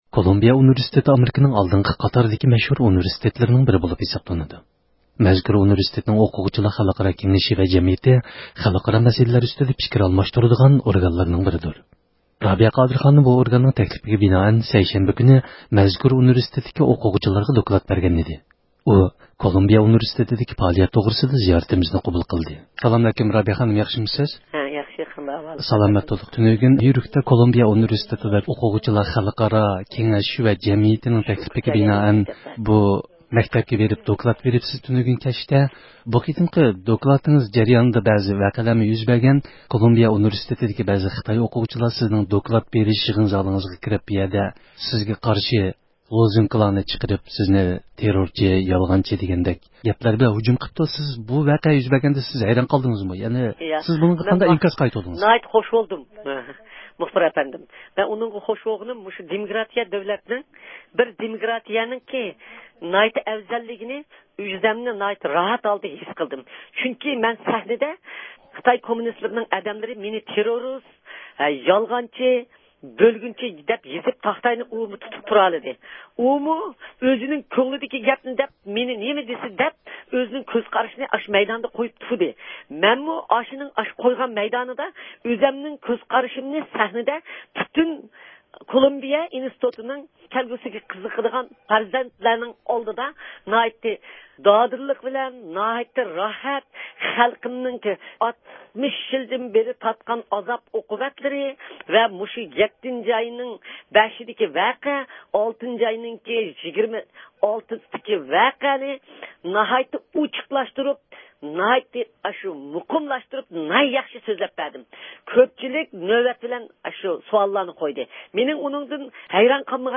يۇقىرىدىكى ئاۋاز ئۇلىنىشىدىن، رابىيە قادىر خانىم بىلەن كولومبىيە ئۇنىۋېرسىتېتىدىكى دوكلات بېرىش يىغىنى توغرىسىدا ئۆتكۈزگەن سۆھبىتىمىزنىڭ تەپسىلاتىنى دىققىتىڭلارغا سۇنىمىز.